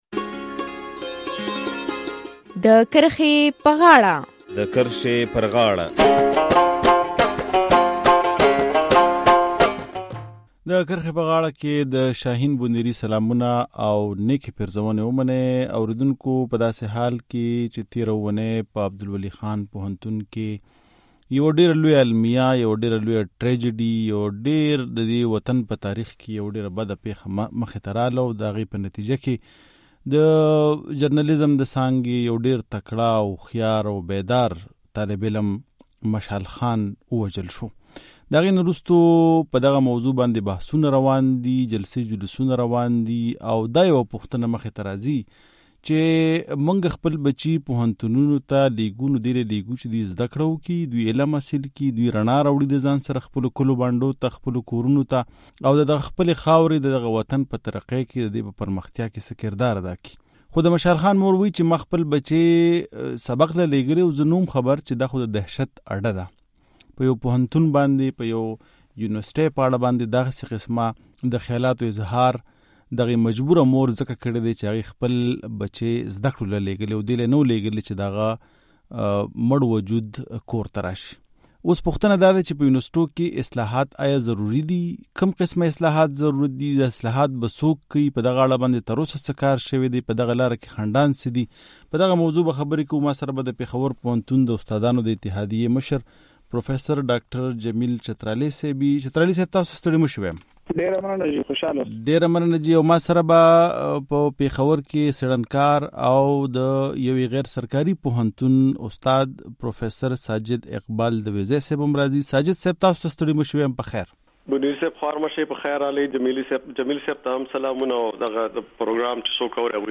په عبدالولي خان پوهنتون کې د زده کوونکي مشال خان تر وژنې وروستو دا پوښتنه را-اوچته شوې ده چې پوهنتونونه ولې د علم مرکزونو پرځای د ویرې او دهشت مرکزونه جوړ کړل شوي دي؟ د کرښې پر غاړه خپرونه کې پر دغه موضوع بحث کوو.